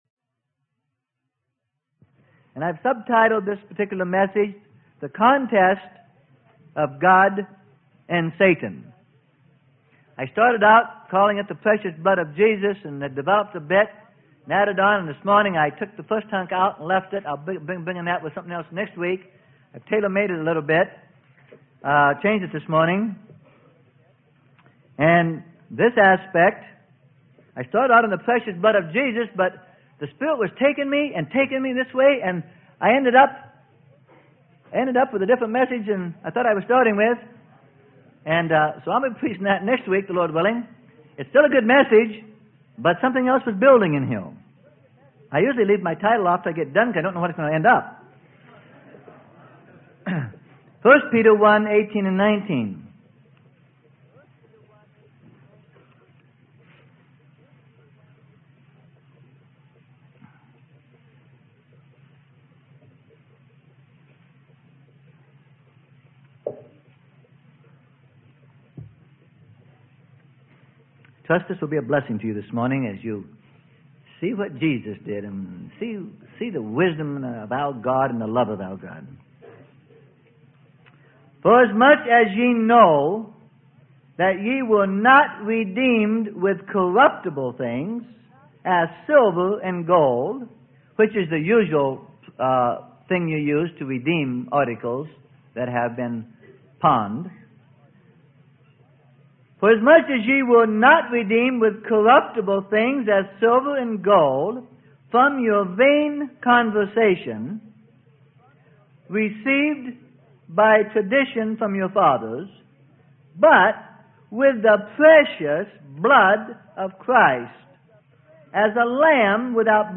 Sermon: Through Death With Him - Part 14: The Contest of Good and Evil - Freely Given Online Library